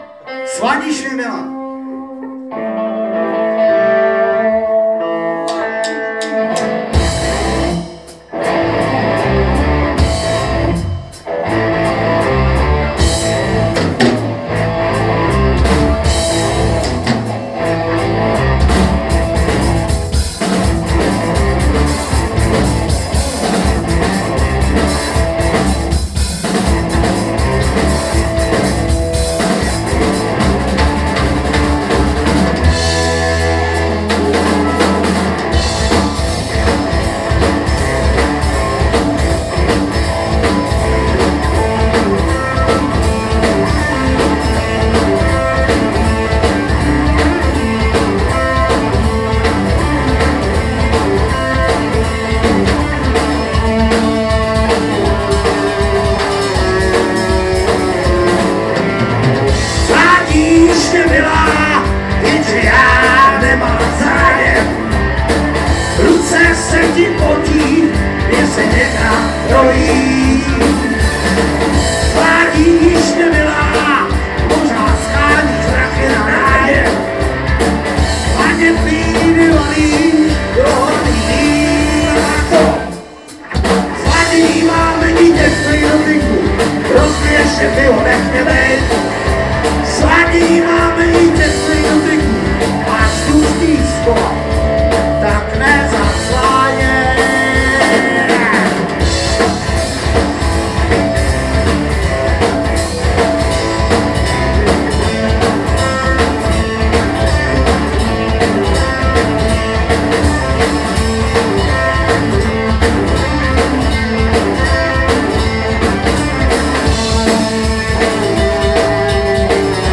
Má to v sobě limiter.
Zase X-Y tentokrat asi 3m  před podiem u zvukaře.